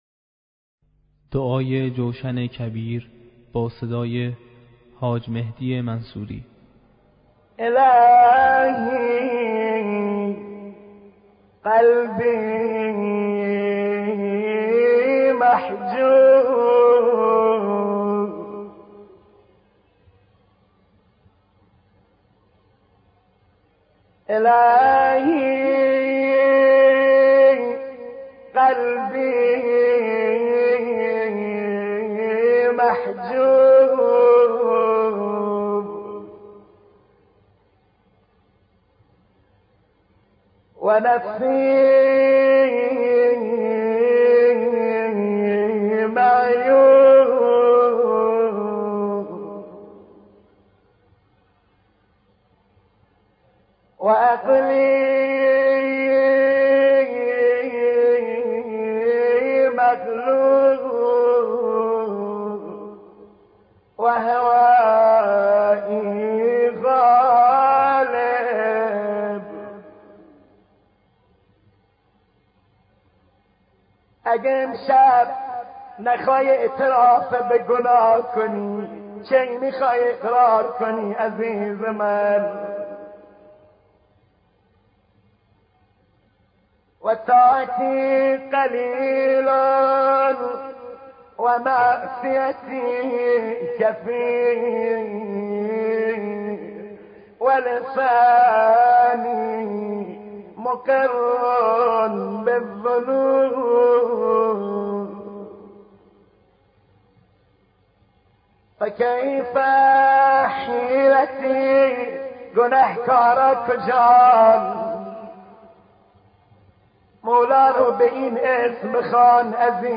با صدای مداحان مختلف